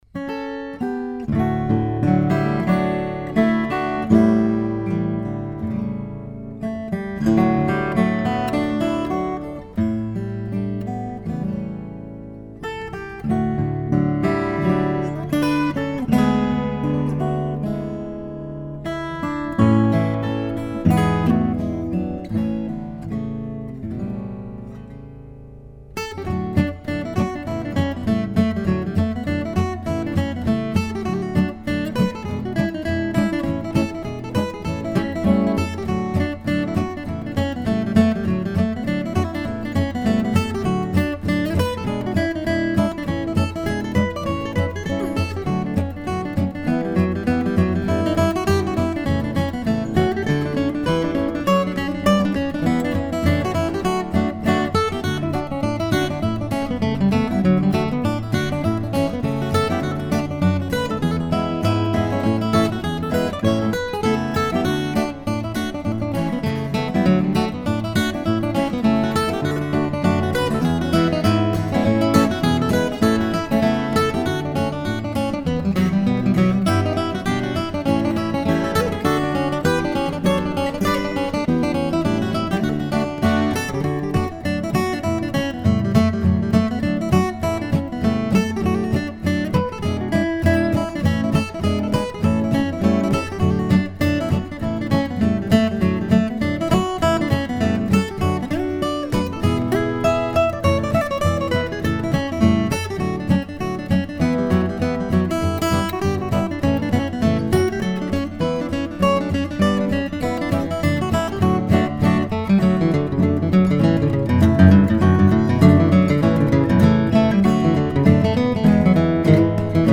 Vintons_Hornpipe.mp3